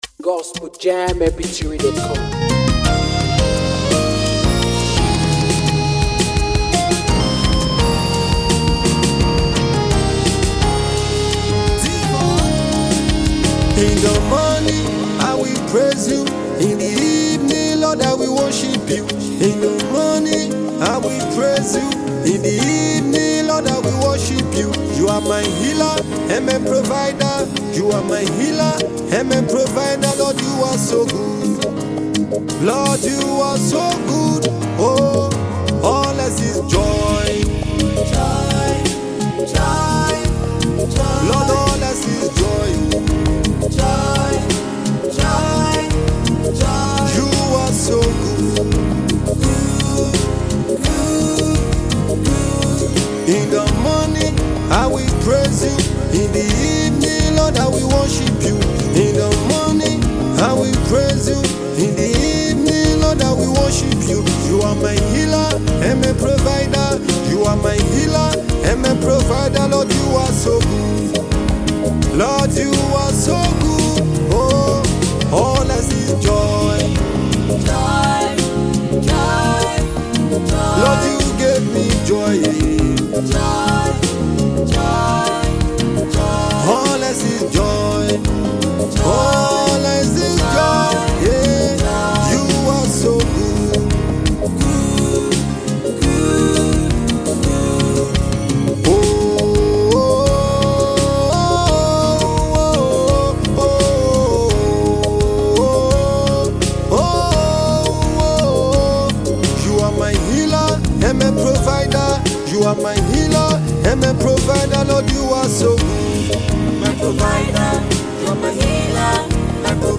Afro gospel